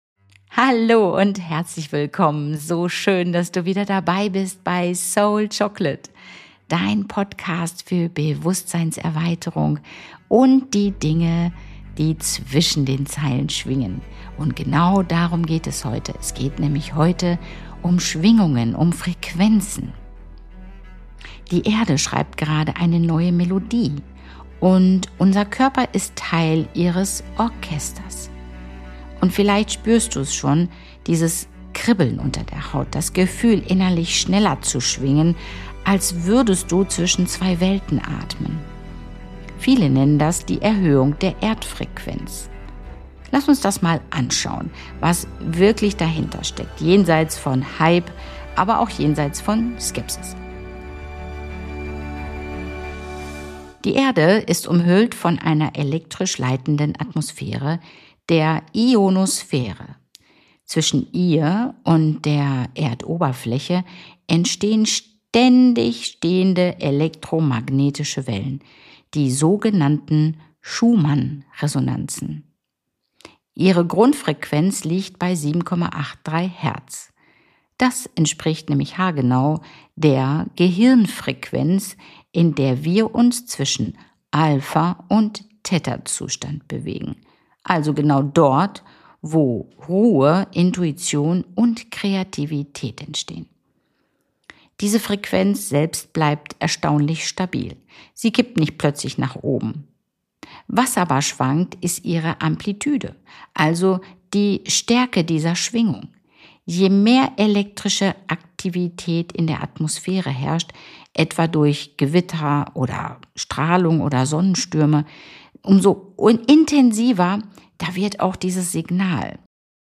Du erfährst, warum besonders sensible oder ältere Menschen darauf reagieren und wie du dich mit Licht, Wasser, Atem und Erdung stabilisierst. Eine sanfte Meditation führt dich zurück in deine Mitte – und Huna-Energiearbeit kann helfen, Körper und Erde wieder in Einklang zu bringen.